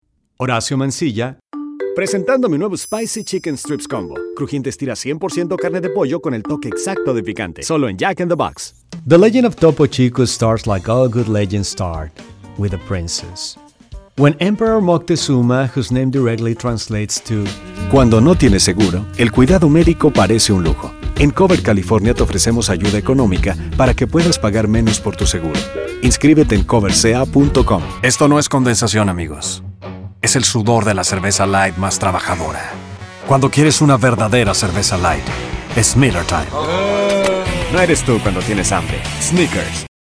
Spanish Commercial